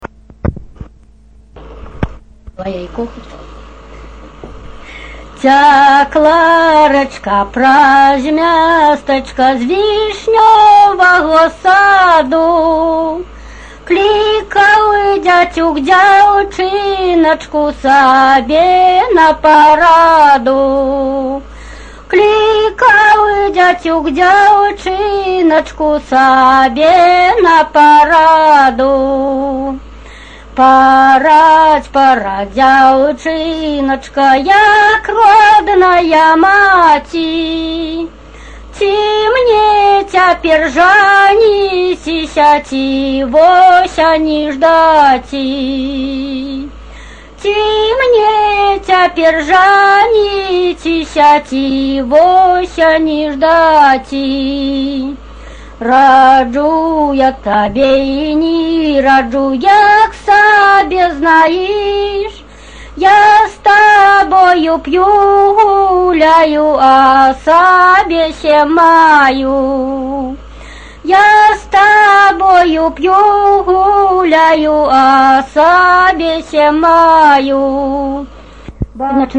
4. Мінская вобласць
Тема: ЭБ БГУ::Беларускі фальклор::Пазаабрадавая паэзія::любоўныя песні